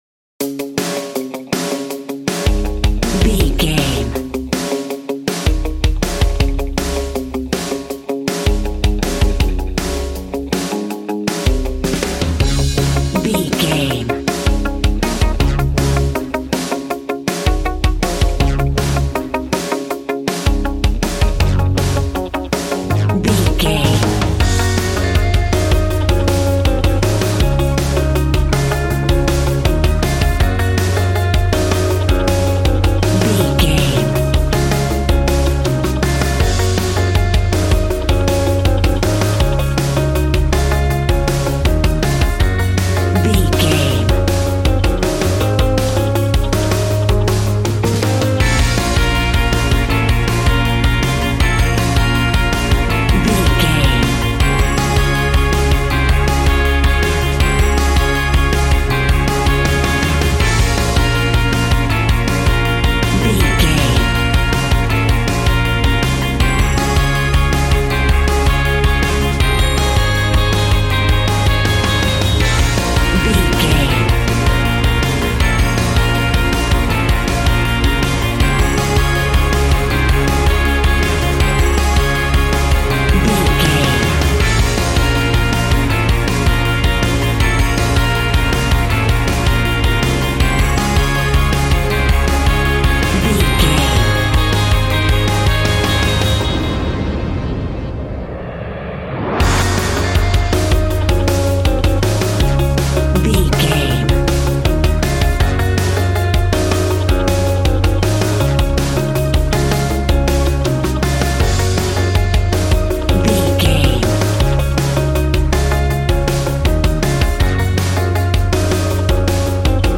Ionian/Major
D♭
groovy
happy
electric guitar
bass guitar
drums
piano
organ